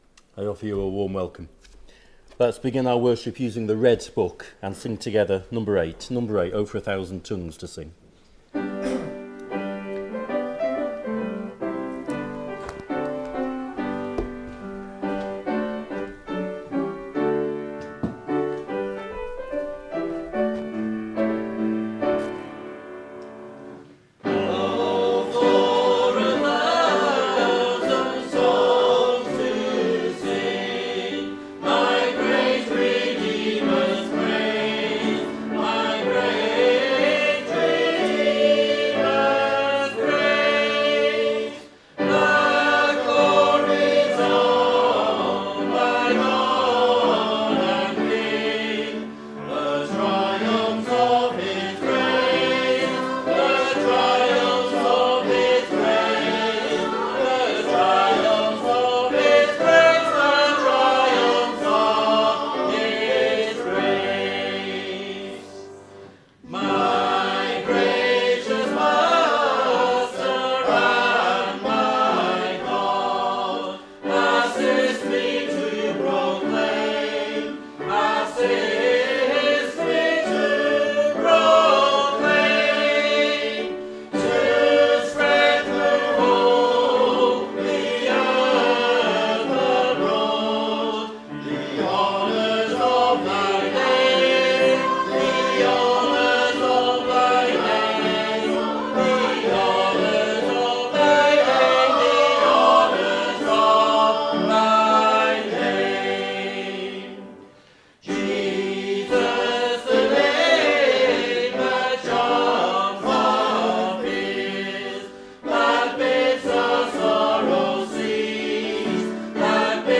Below is audio of the full service.
2025-12-14 Evening Worship If you listen to the whole service on here (as opposed to just the sermon), would you let us know?